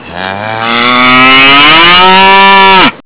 Megan is the larger of the two and makes the most noise!
moo.wav